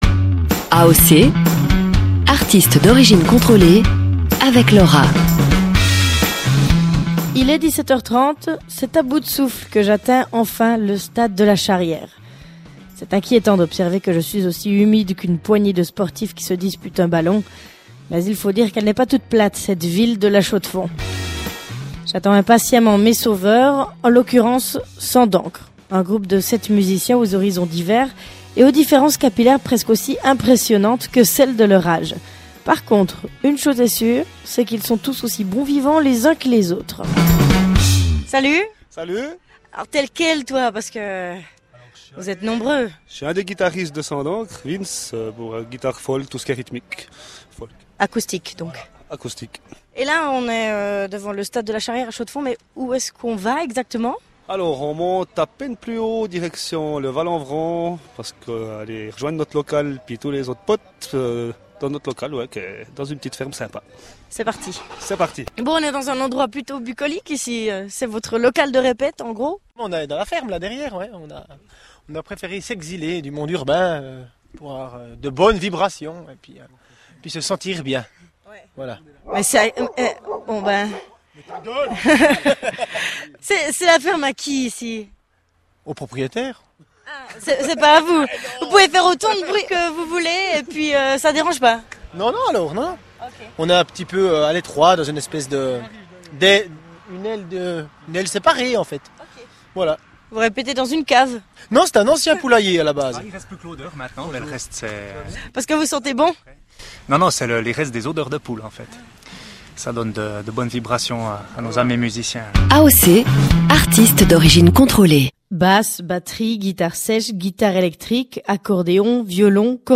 Radio
interview-rtn.mp3